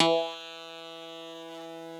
genesis_bass_040.wav